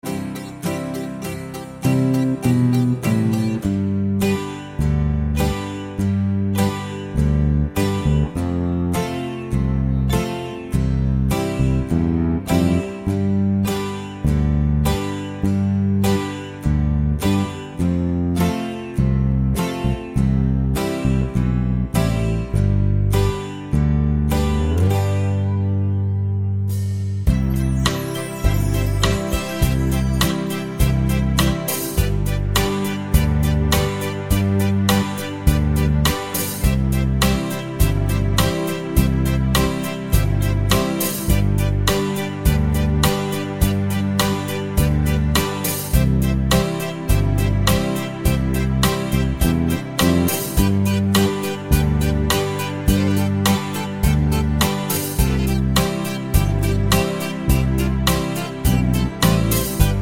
no Backing Vocals Soundtracks 4:17 Buy £1.50